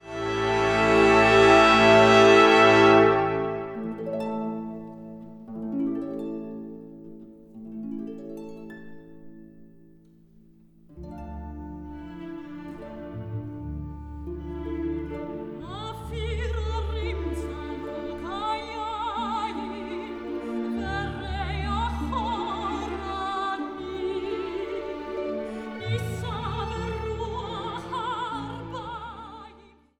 Zang | Solozang
Instrumentaal | Orkest